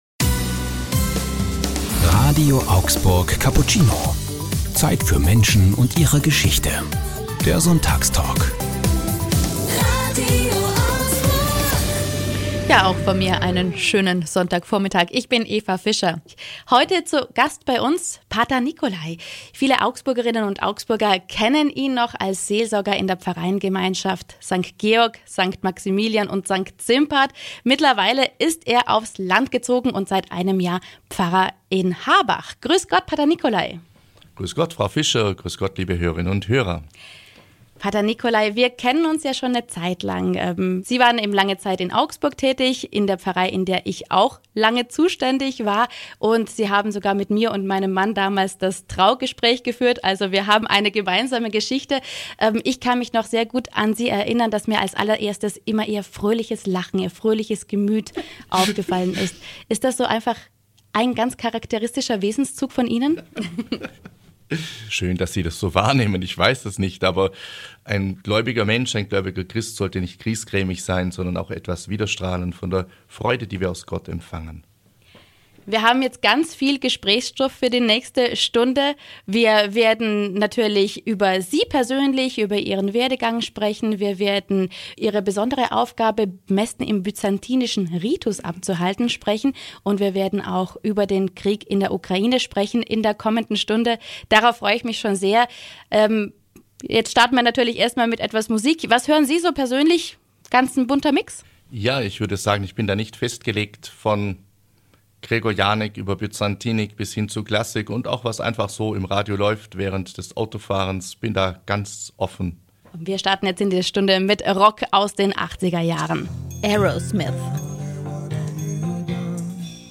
In unserem RADIO AUGSBURG Sonntagstalk sprechen wir mit ihm darüber, wie diese Liebe zur Ostkirche entflammt ist und wie im Krieg in der Ukraine Frieden gelingen kann.